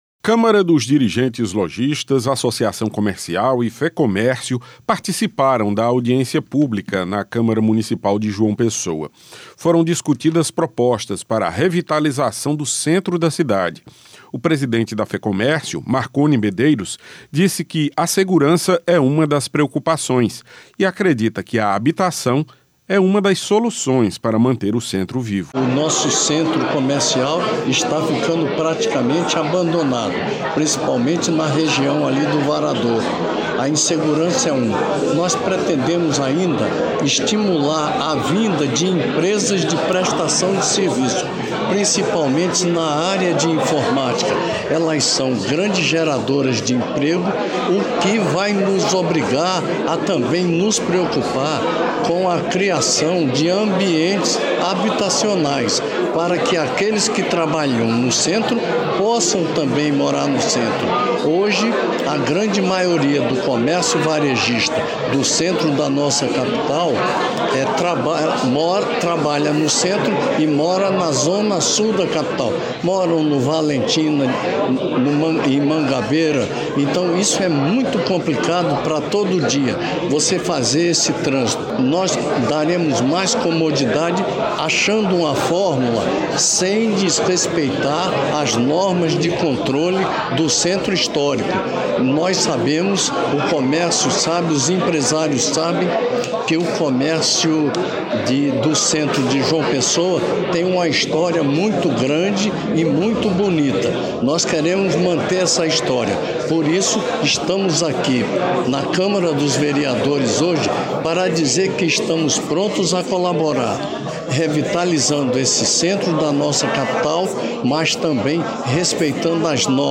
Reportagem: audiência pública debate intervenções no centro histórico da capital